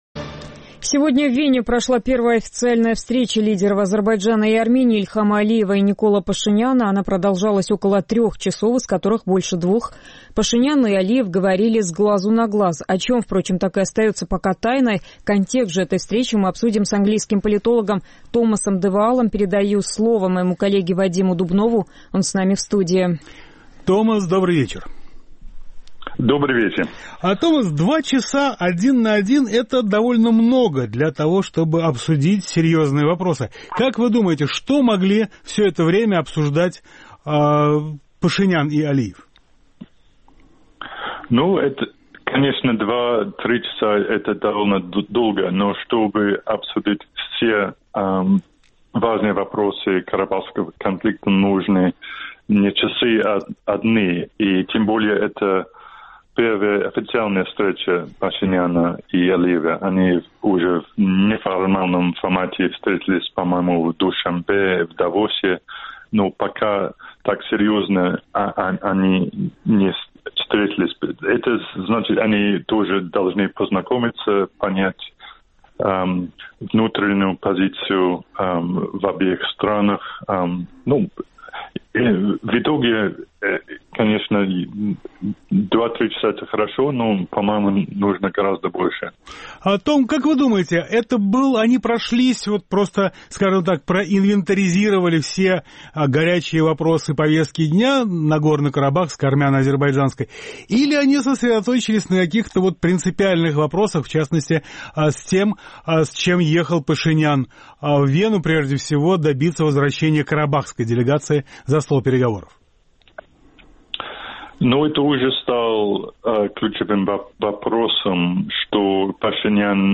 Гость недели – Томас де Ваал